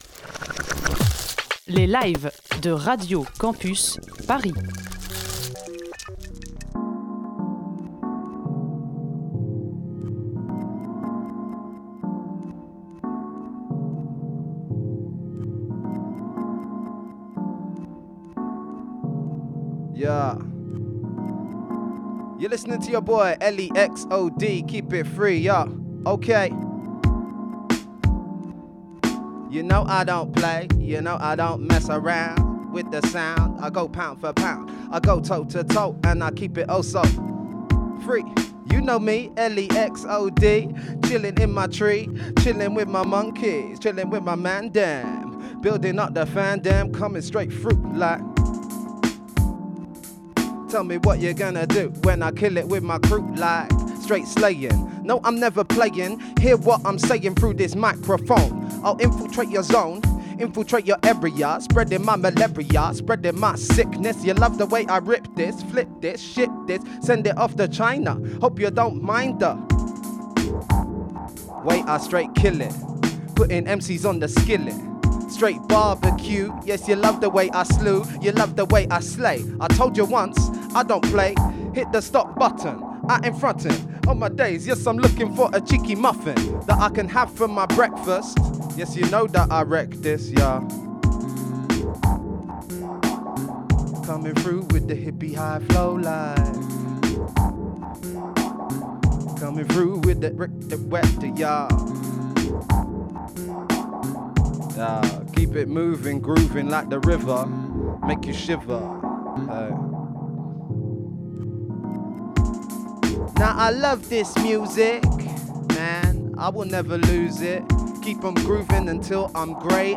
session live
interview